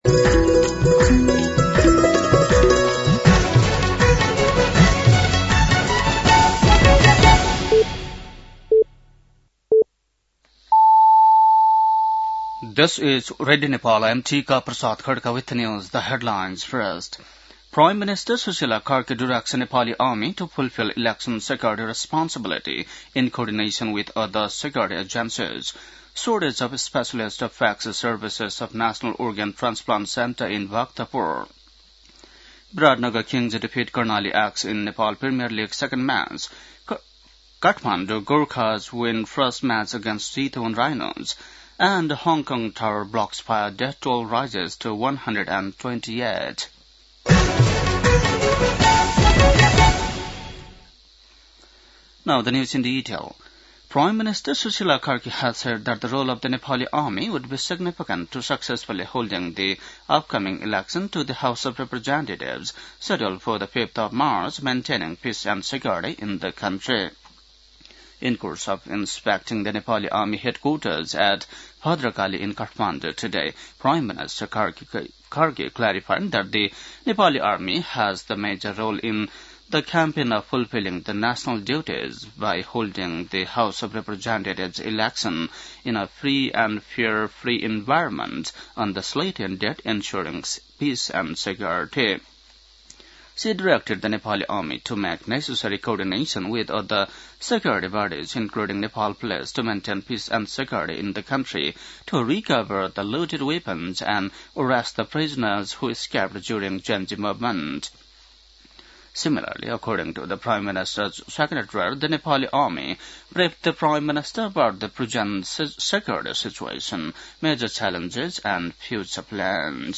बेलुकी ८ बजेको अङ्ग्रेजी समाचार : १२ मंसिर , २०८२